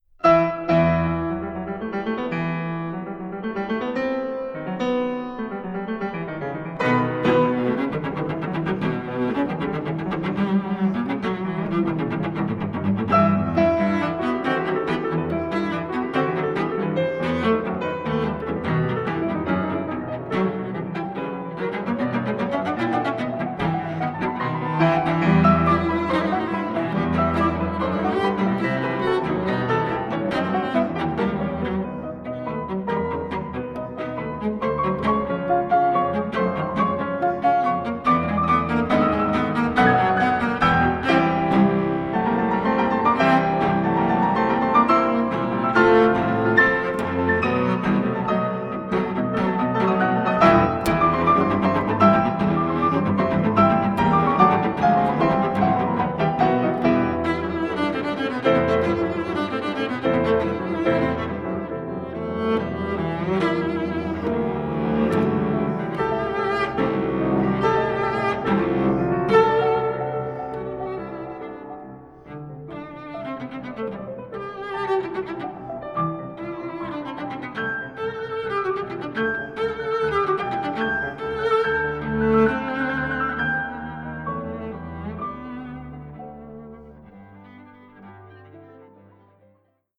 Cello
Klavier